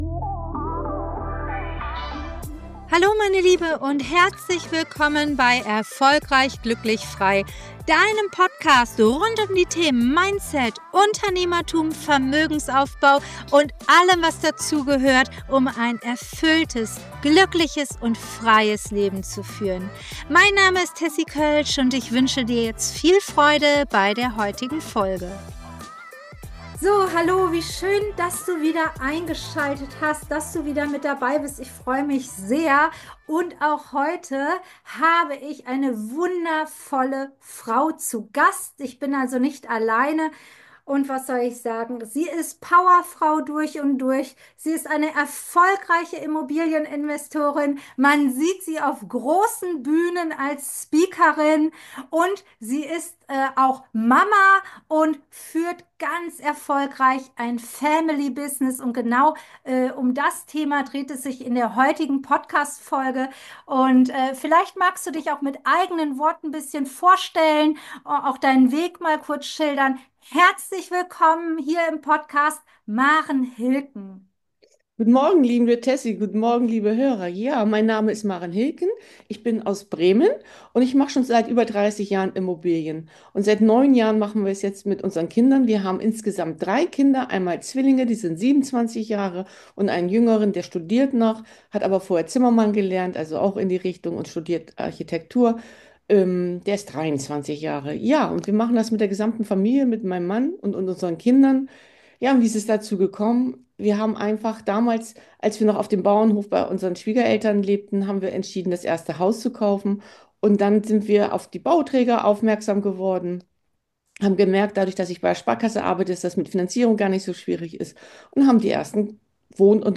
#5 Familie als Business-Booster für nachhaltigen Erfolg – Interview